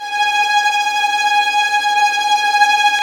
Index of /90_sSampleCDs/Roland LCDP13 String Sections/STR_Violins Trem/STR_Vls Tremolo